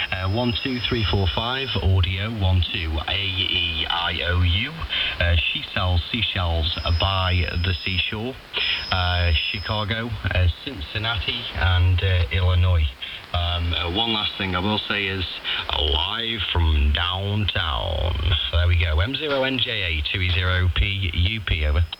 Recorded using Kenwood VM TS-950SDX @ 0 - 6000Hz
To appreciate the full fidelity of the enhanced frequency response.